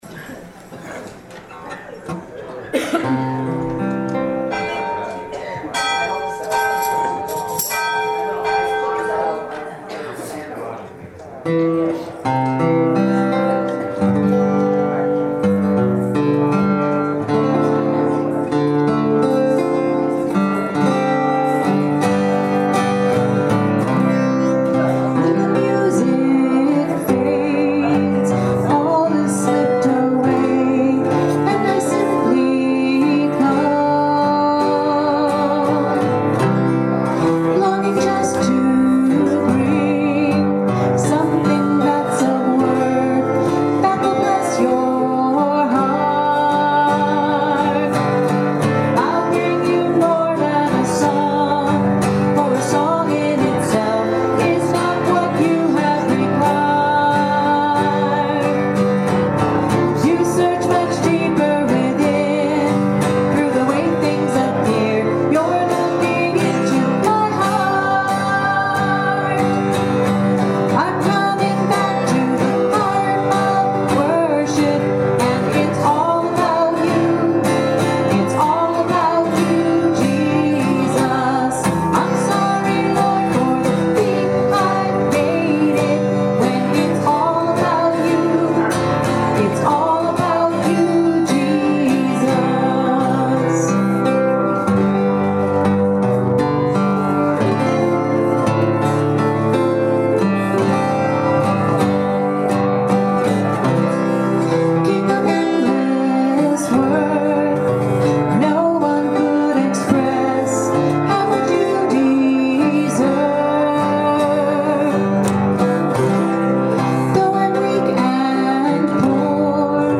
Call To Worship: Responsive Reading: #378 A Litany of Thanksgiving Invocation and Lord’s Prayer (Debts/Debtors) Gloria Patri